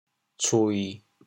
“催”字用潮州话怎么说？